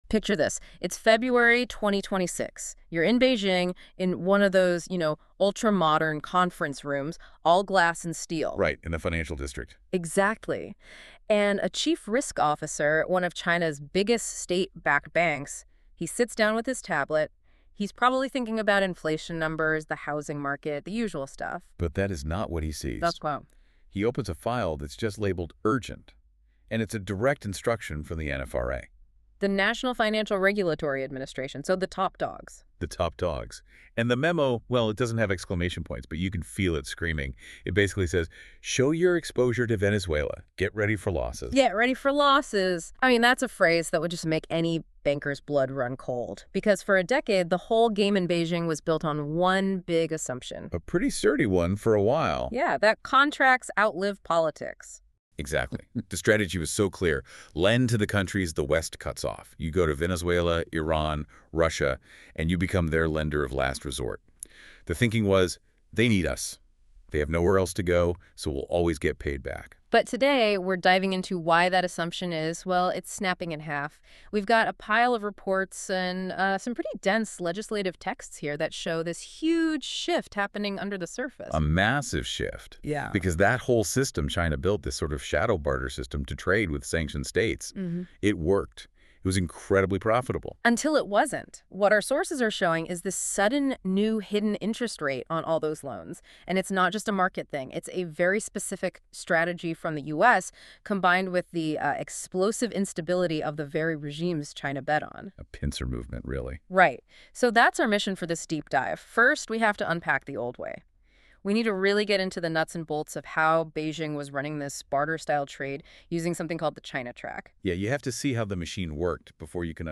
Related Podcasts These podcast episodes are produced with AI voice technology. While we strive for accuracy, please be aware that the voices and dialogue you hear are computer-generated.